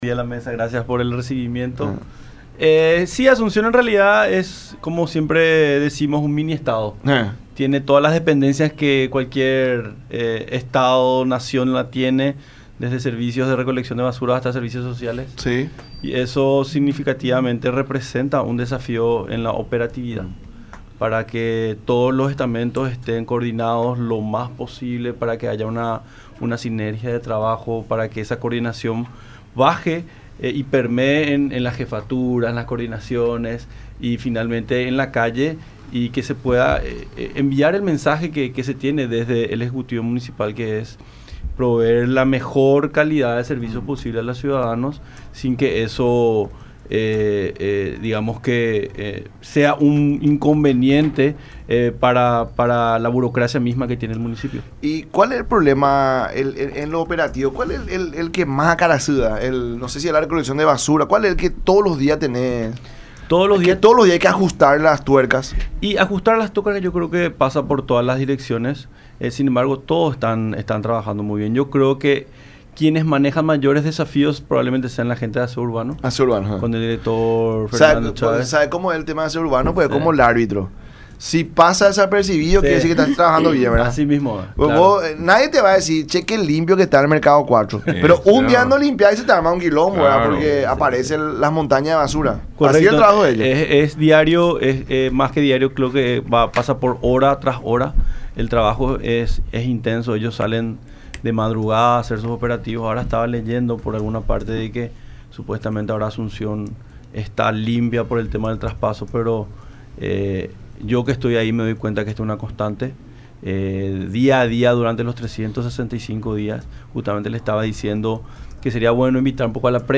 entrevista con el programa “La Mañana de Unión” por Unión TV y radio La Unión.